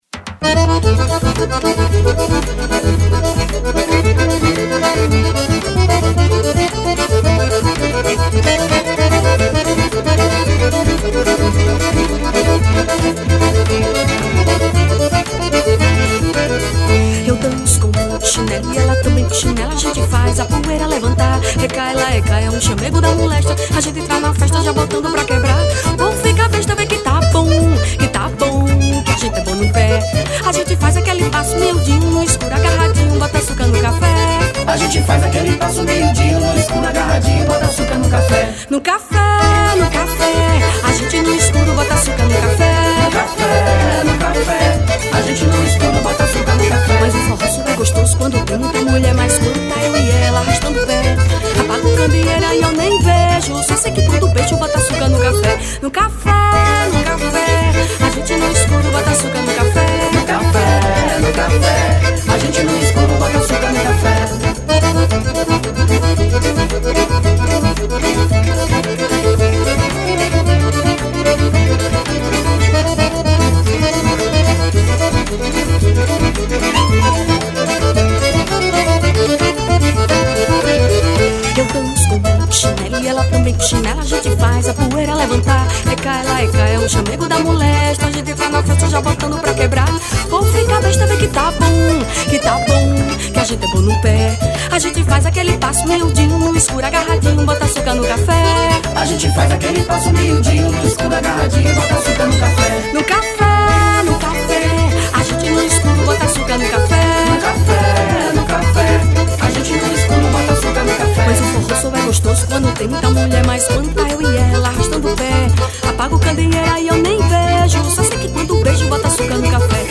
• Acordeom
• Violino
• Zabumba
• Triângulo e percussão
• Baixo e cavaquinho
• Backing Vocal